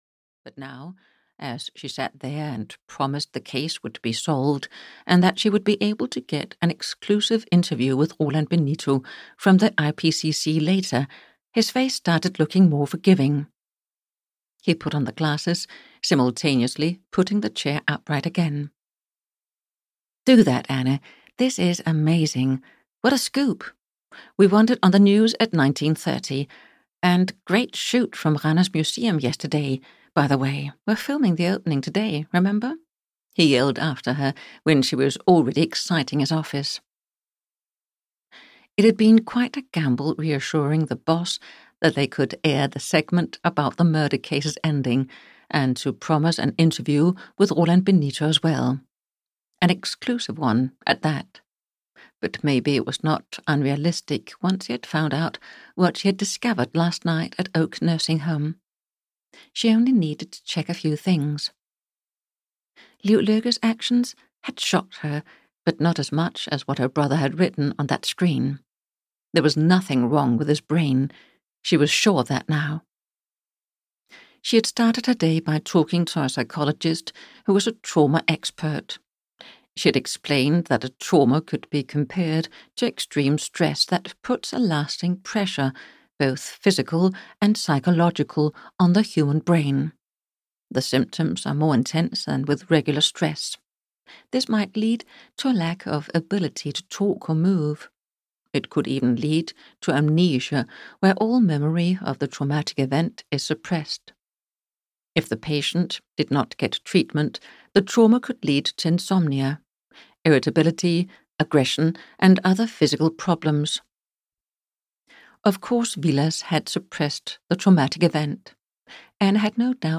Audiobook Burning guilt, written by Inger Gammelgaard Madsen.
Ukázka z knihy